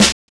Snare set 2 003.wav